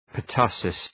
Προφορά
{pər’tʌsıs} (Ουσιαστικό) ● κοκκίτης